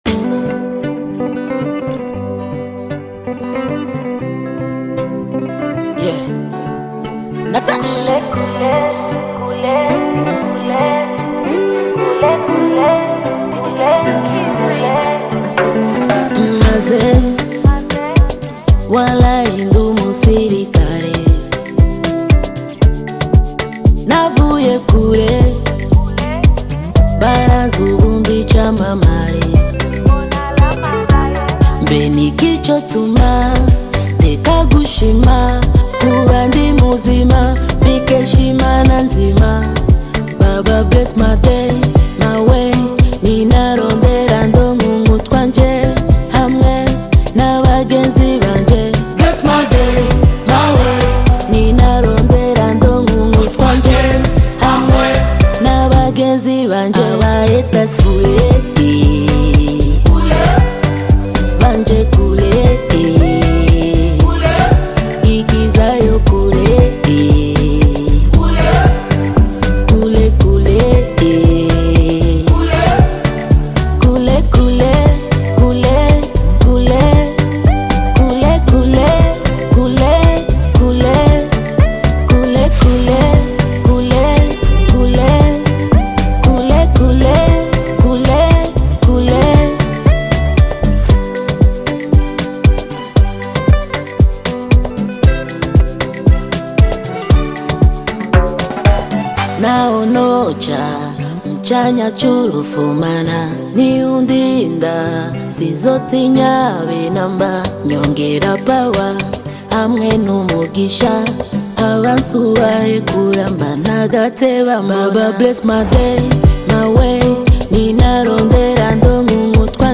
known for her energetic and vibrant musical style.
traditional African rhythms and contemporary beats
powerful voice